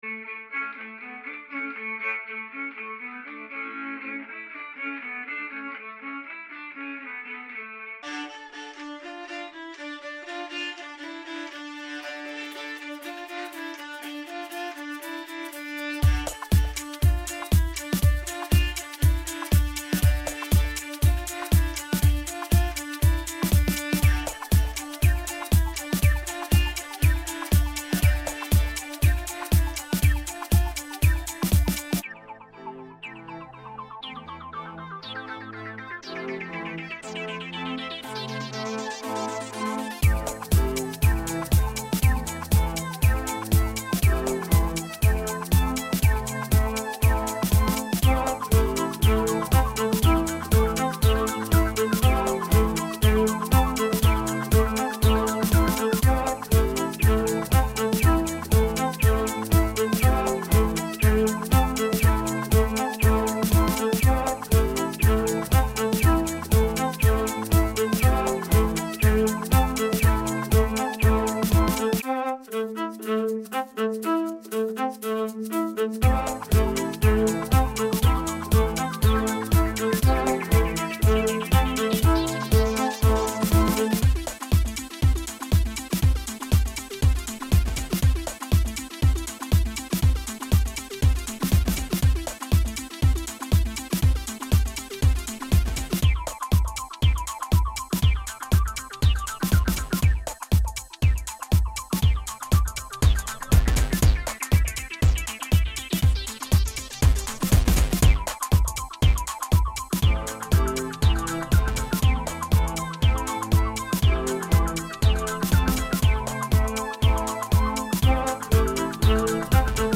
This is my first attempt at composing music on my computer.